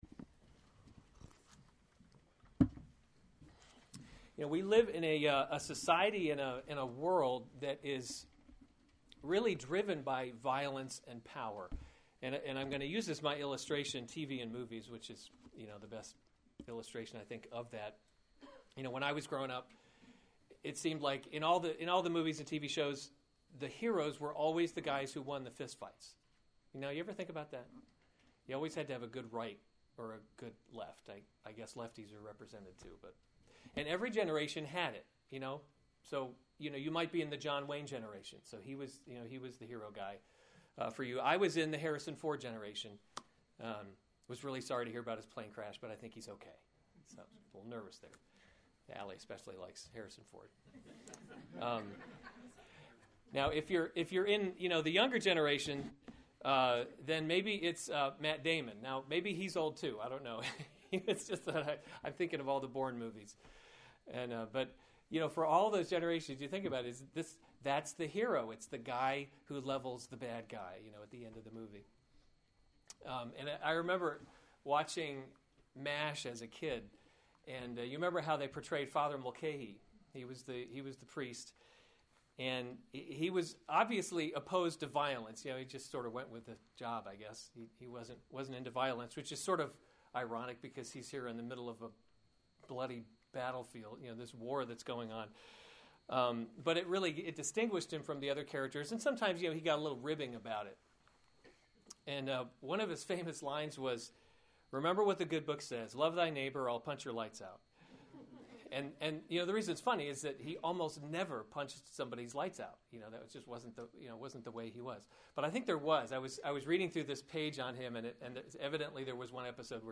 March 7, 2015 Romans – God’s Glory in Salvation series Weekly Sunday Service Save/Download this sermon Romans 12:14-21 Other sermons from Romans 14 Bless those who persecute you; bless and do […]